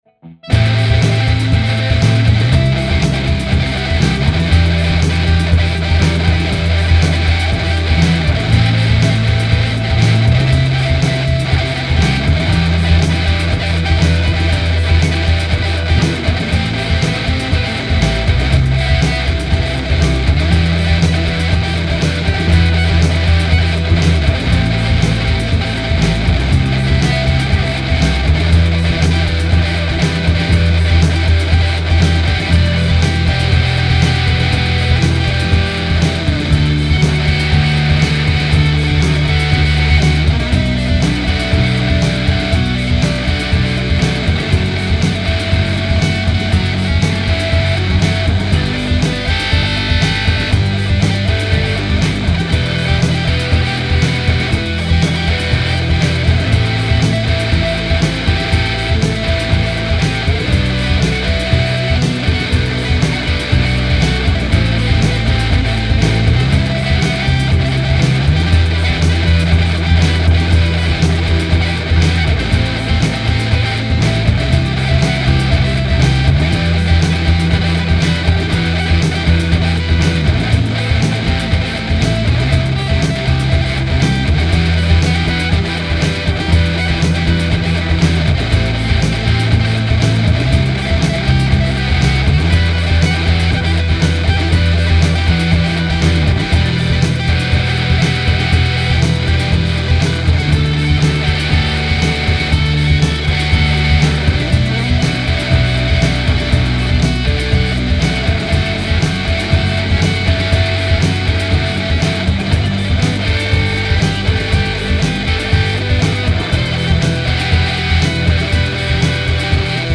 This mix is a little rougher than I’d like but I know I’m going to add vocals to this one and I need to work out the lyrics before I decide on the final structure of the tune. I like the textures even if they are a bit on the heavy side. So yeah, it’s a rough cut.